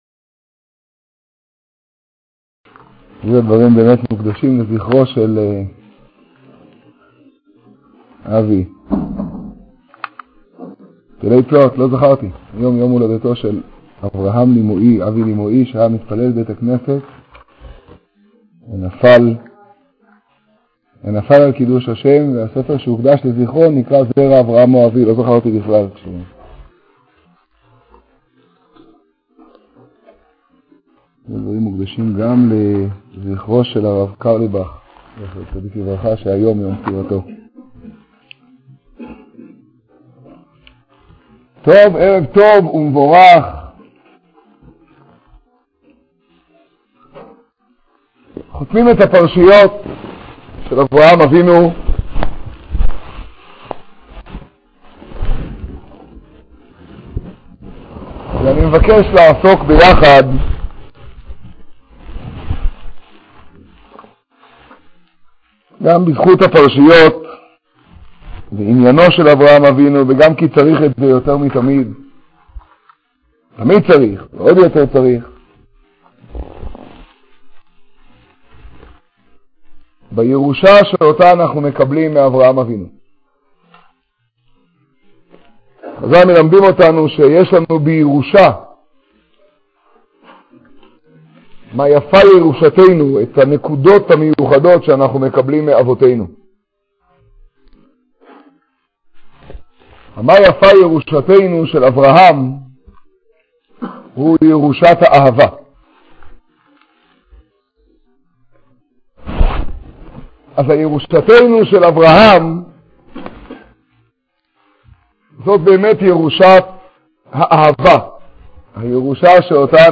השיעור בירושלים, פרשת חיי שרה תשעא.